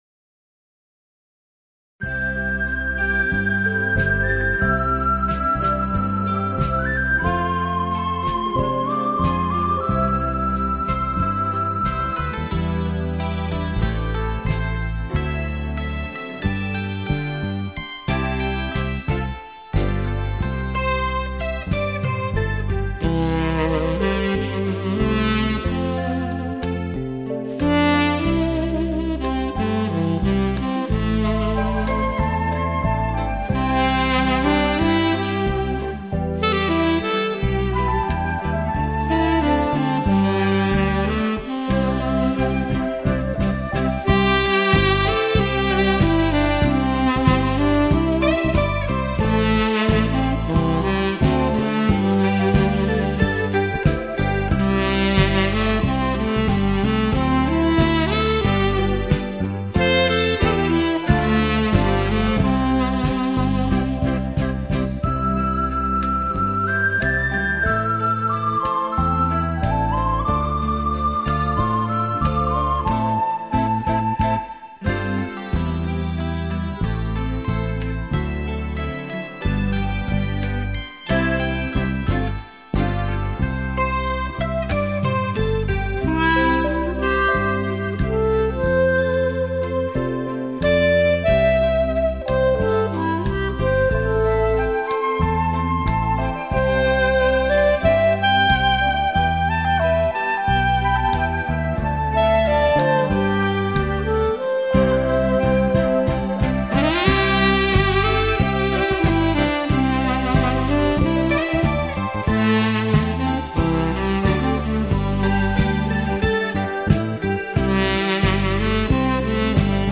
随着萨克斯风乐音的吹奏，
可让聆听的人能很自然的以轻松无压的情绪进入到音乐之中。
这轻柔音乐如水般拂过你的全身，